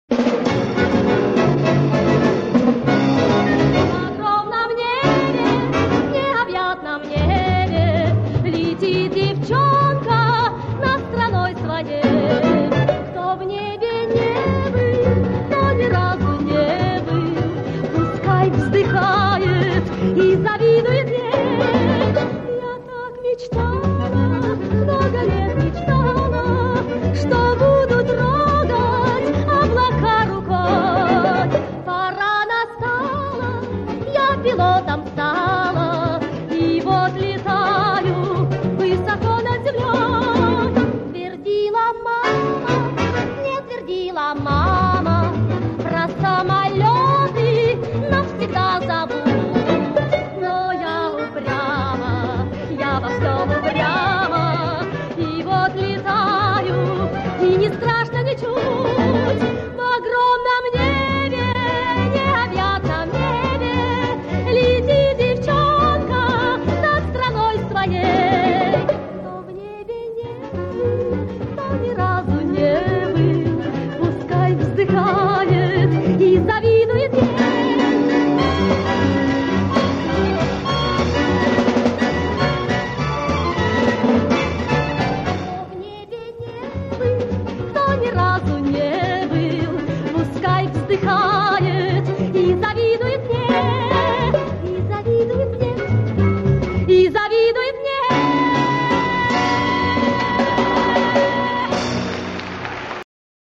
Похоже эти записи с радиоприемника 3-го класса.